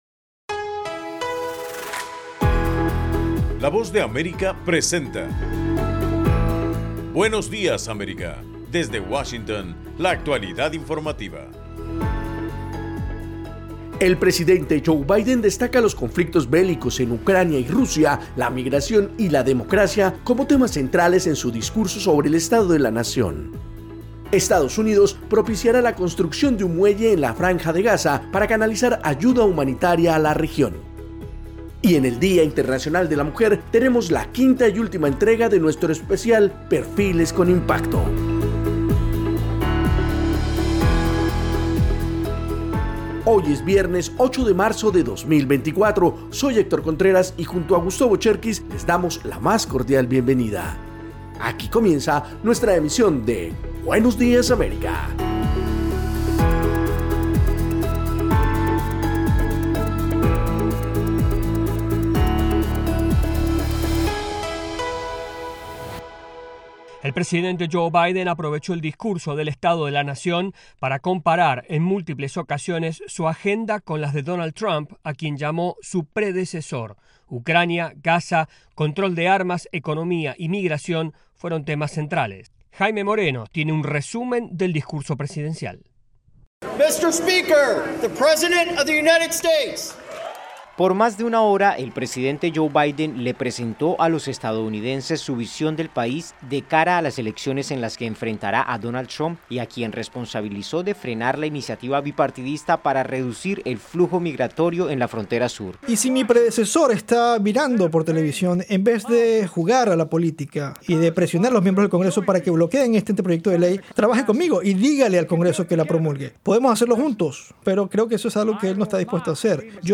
En el programa de hoy, 8 de marzo, el presidente Joe Biden destaca los conflictos bélicos en Ucrania y Rusia, la migración y la democracia en su discurso sobre el Estado de la Nación. Esta y otras noticias de Estados Unidos y América Latina en Buenos Días América, un programa de la Voz de América.